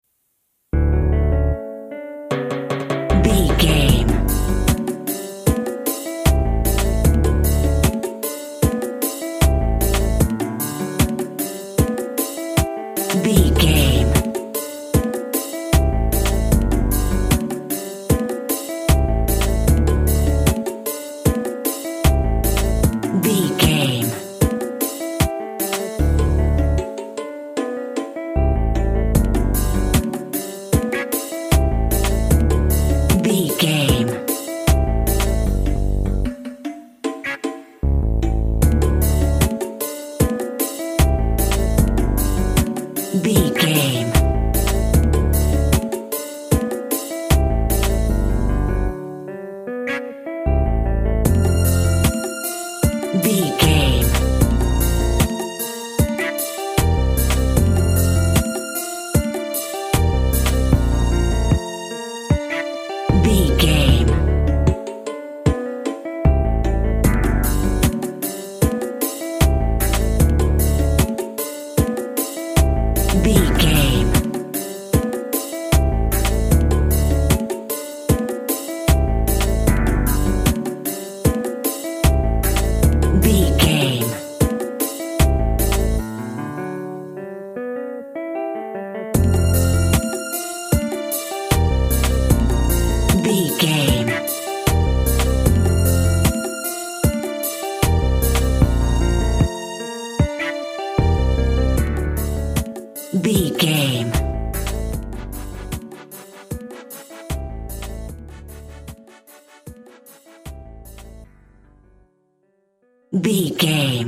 Also with small elements of Dub and Rasta music.
Ionian/Major
tropical
drums
bass
guitar
piano
brass
steel drum